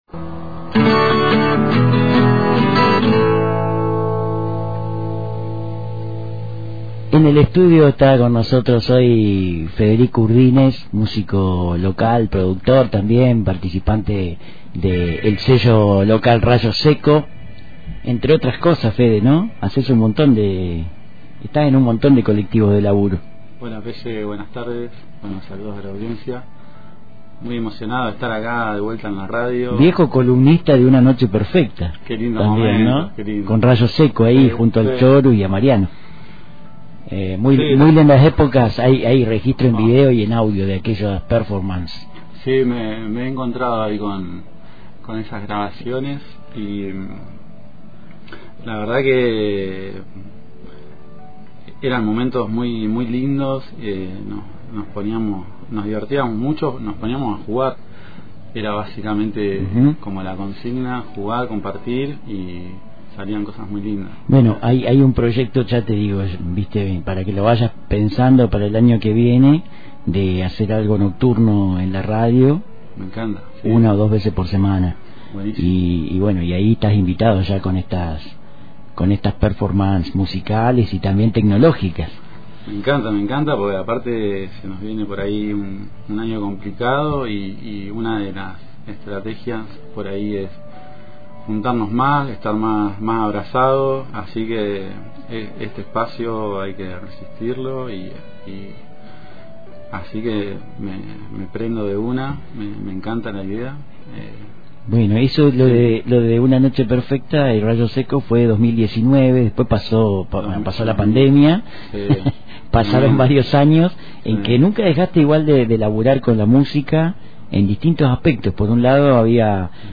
También nos mostró la loopera que él mismo creó con un viejo grabador analógico a pilas, en donde un cassette con cinta de corta duración puede grabar y luego reproducir una y otra vez lo registrado, a la manera de cualquier loopera moderna y digital. Algo de eso trajo a la radio, para jugar un rato en vivo, sumando también trompeta, sordina y una imaginación espontánea al servicio de la improvisación.